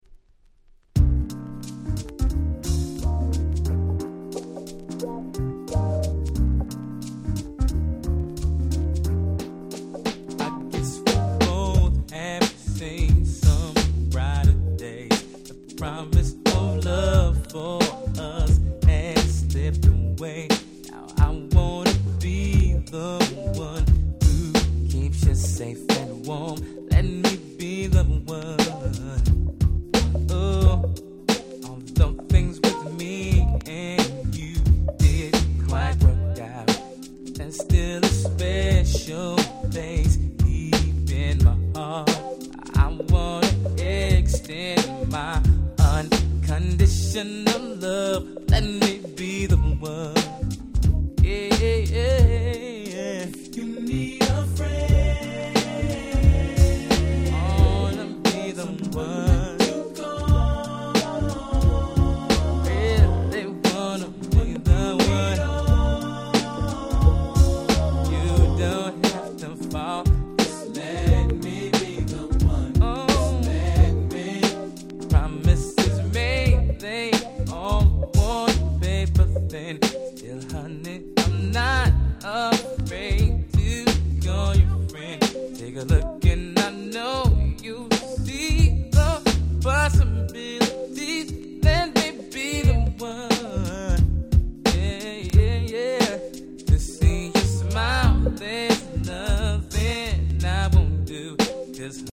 97' Smash Hit R&B/Neo Soul !!